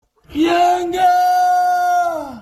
yengaa koomapatti Meme Sound Effect